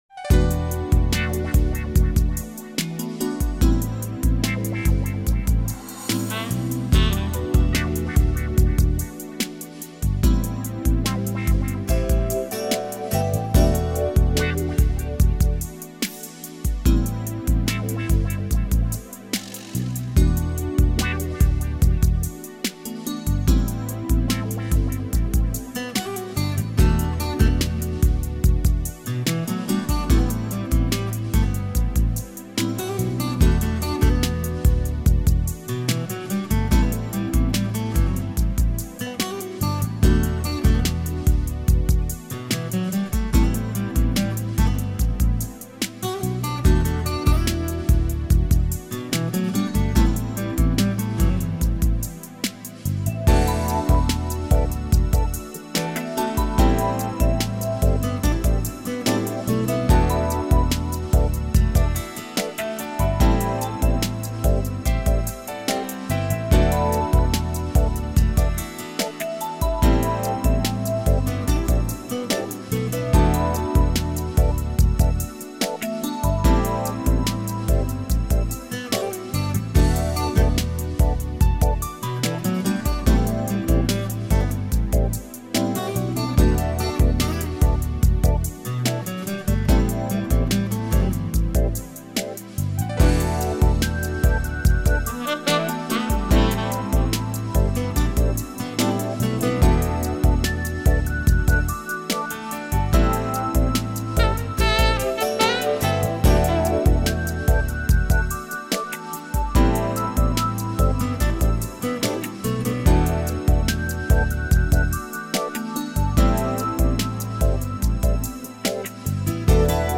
это инструментальная композиция